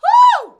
HUH.wav